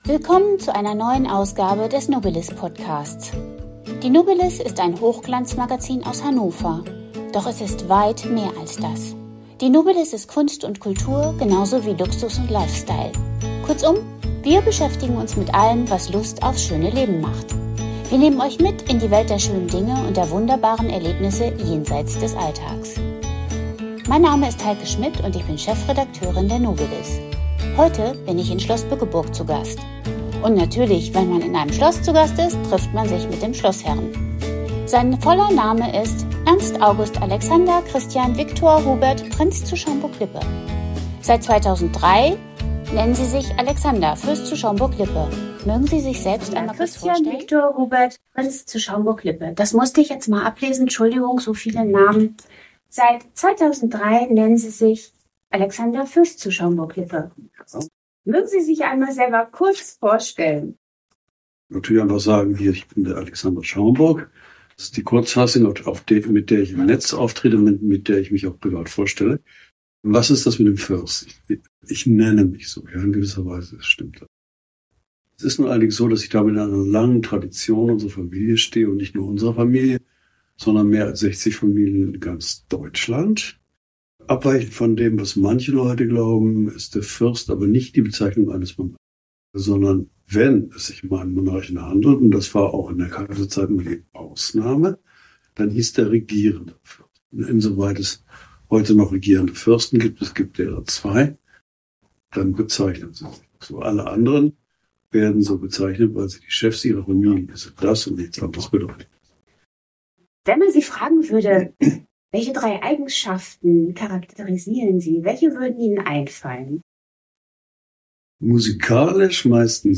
Im Interview mit Fürst Alexander zu Schaumburg-Lippe
Im exklusiven Gespräch im „Roten Salon“, seinem privaten Rückzugsort, plaudert Alexander Fürst zu Schaumburg-Lippe über das harmonische Miteinander seiner außergewöhnlichen Patchwork-Familie, die Bedeutung seines Geburtstags am 25. Dezember und charmante Anekdoten aus seiner Kindheit – wie das rote Band, das den Raum mit den Geschenken geheim hielt.